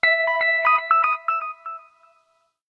09_Echo.ogg